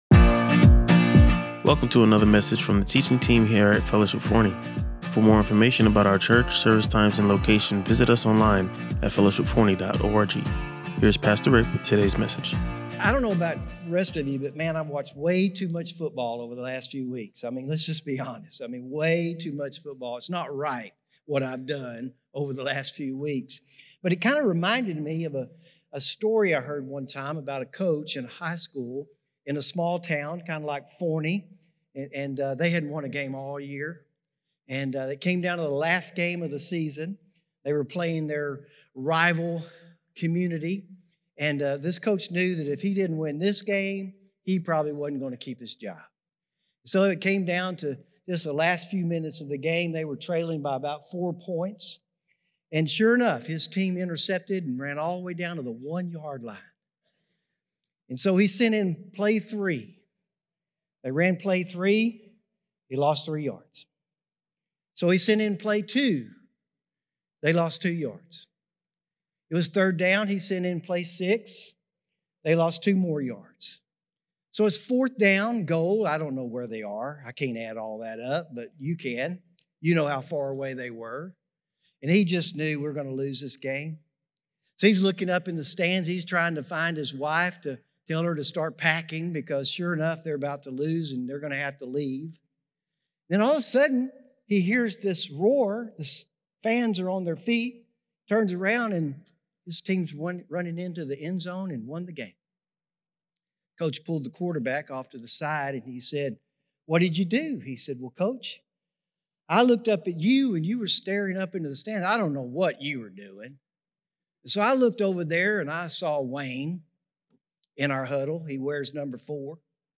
Listen to or watch the full sermon and learn more about finding rest in Christ.